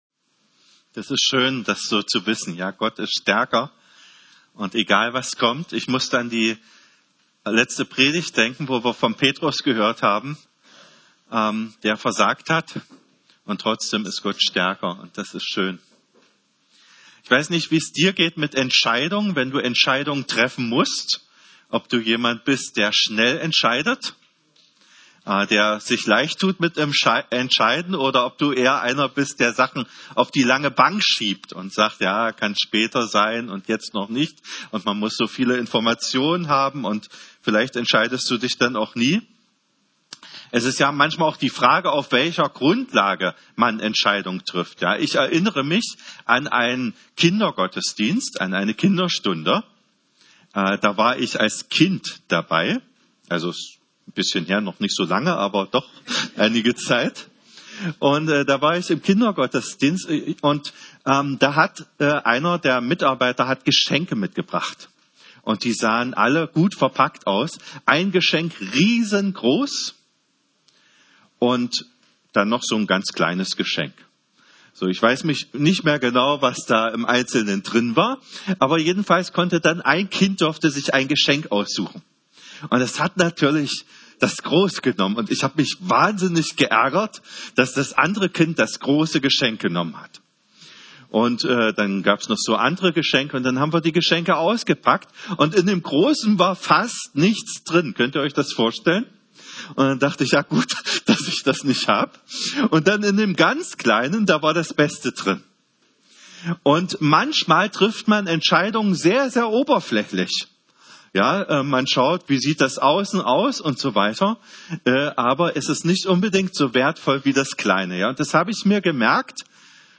Predigten | EG-Meidling 3/54